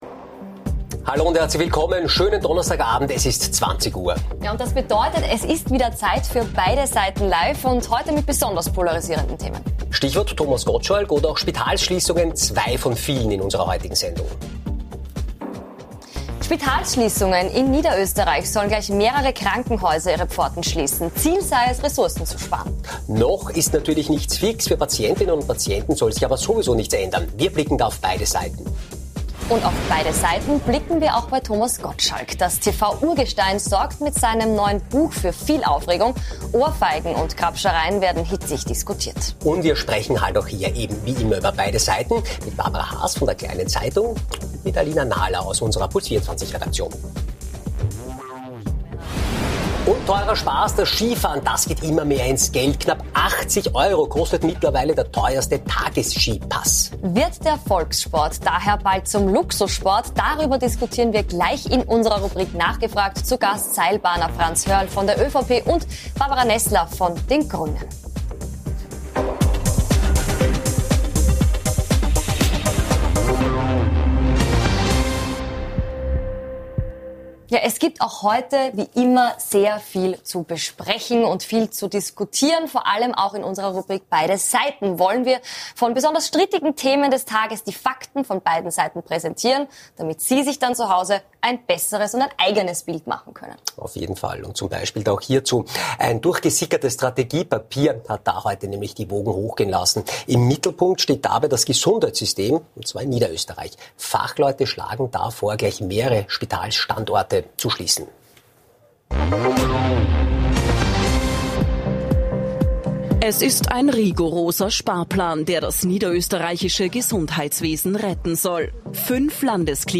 Und nachgefragt haben wir heute bei gleich zwei Gästen - Im großen Beide Seiten Live Duell mit Barbara Neßler von den Grünen und Franz Hörl von der ÖVP.